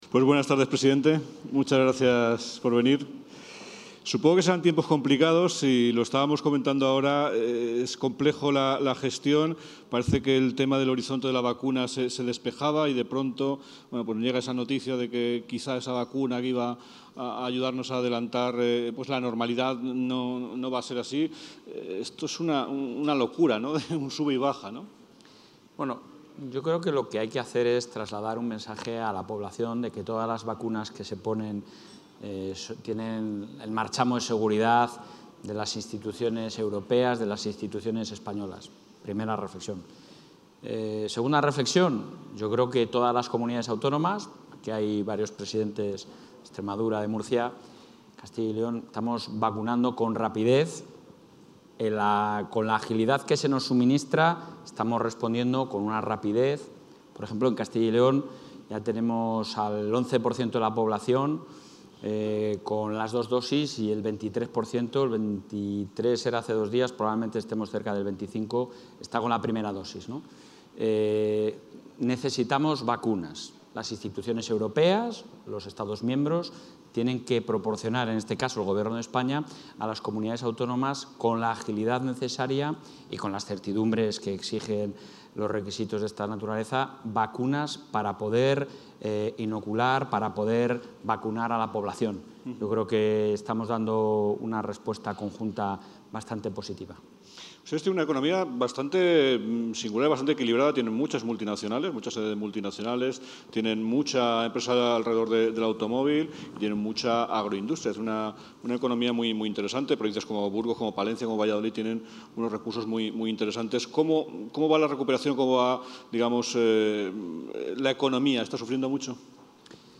Intervención del presidente de la Junta.
Simposio 'Wake up Spain', organizado por El Español